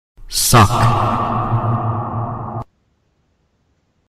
PLAY Succ (echo)
succ-echo-sound-effect-memes-for-editing-video.mp3